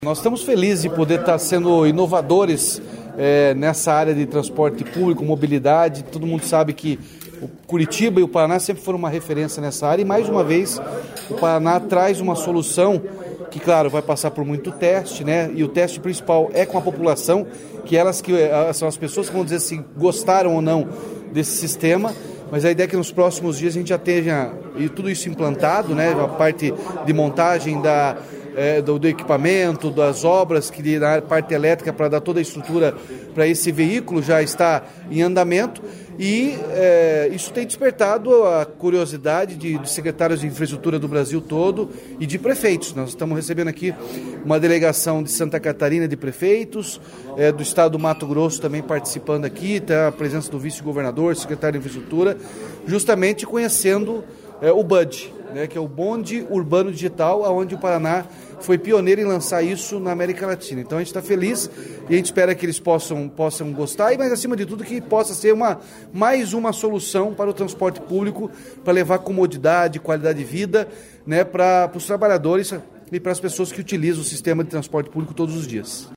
Sonora do governador Ratinho Junior sobre a apresentação do Bonde Urbano Digital a autoridades de Santa Catarina e do Mato Grosso